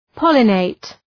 Προφορά
{‘pɒlə,neıt} (Ρήμα) ● γονιμοποιώ άνθος